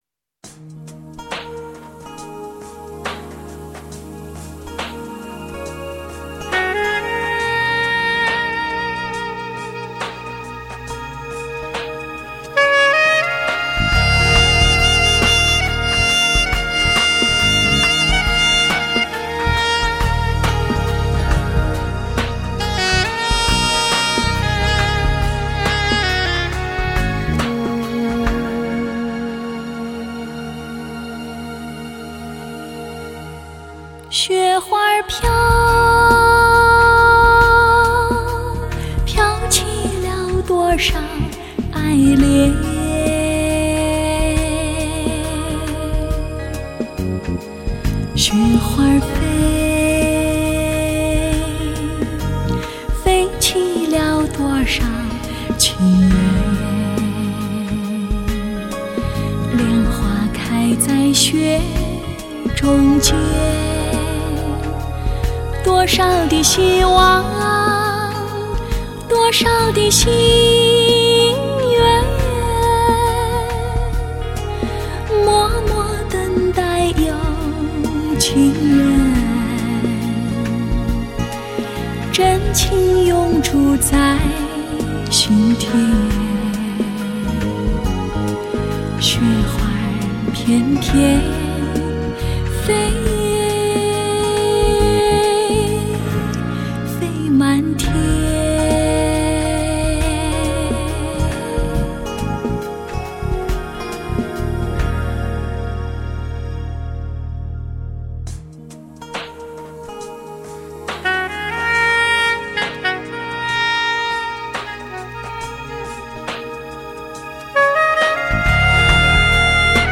演唱甜美圆润，温婉动人，浑然天成的美